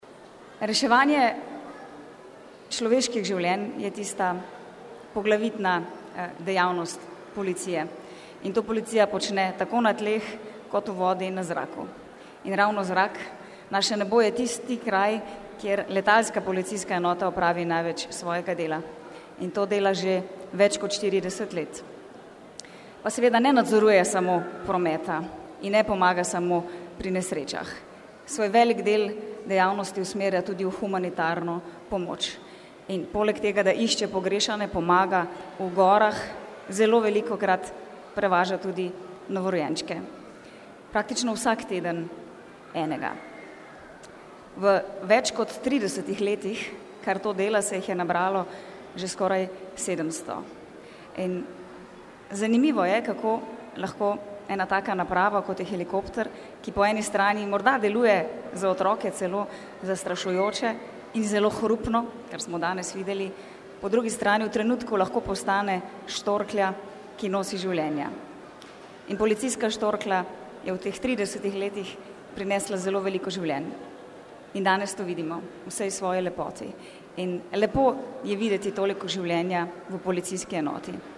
V hangarju Letalske policijske enote je bilo več kot dovolj prostora za dobro voljo in veselje.
Slednja je v pozdravnem nagovoru poudarila, da policijski helikopterji poleg svojih rednih nalog opravljajo še humanitarne naloge, od leta 1979 tudi prevoze nedonošenčkov. Dejala je, da so lahko piloti in ostali zaposleni v Letalski policijski enoti na takšne dosežke upravičeno ponosni.